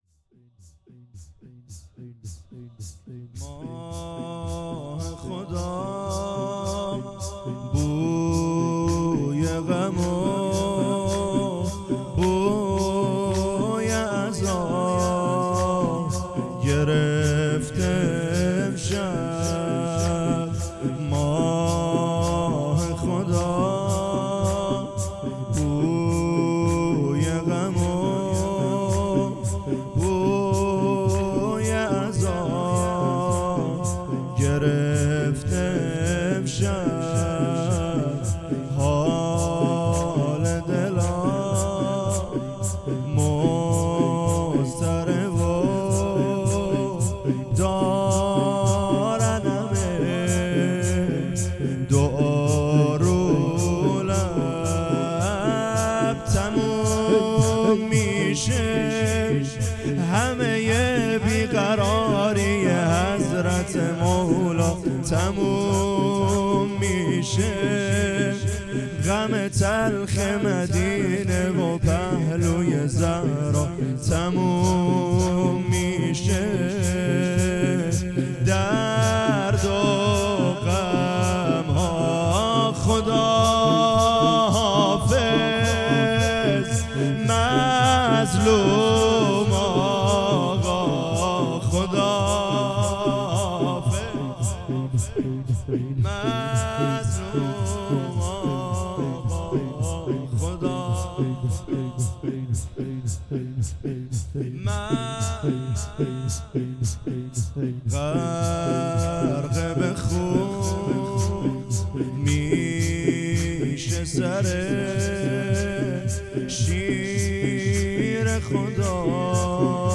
شهادت حضرت علی ع
مداحی اهواز